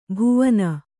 ♪ bhuvana